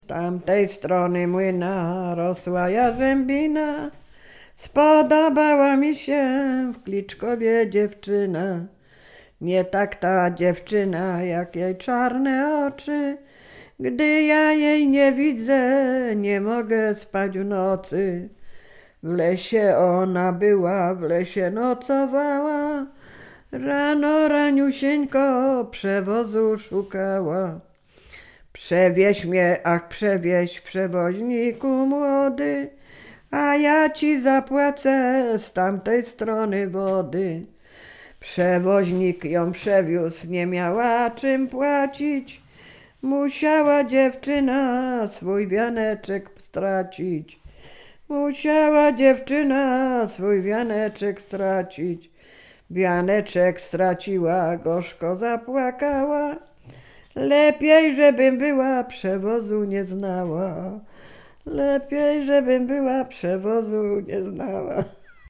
Sieradzkie
liryczne miłosne